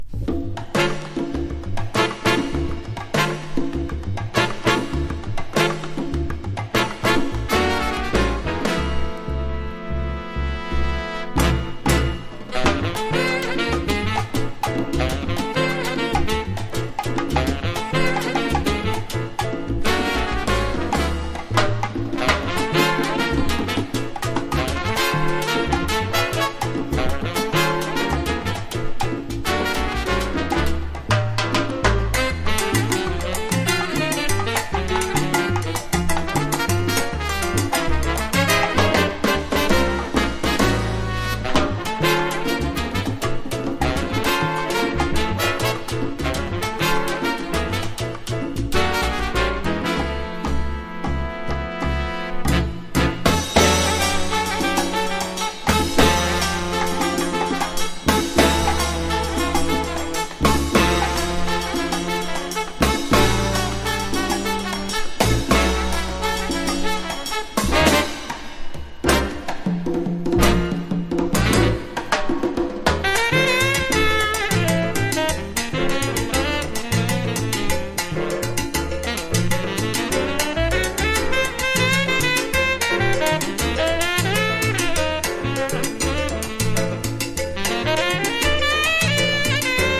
1. JAZZ >
# LATIN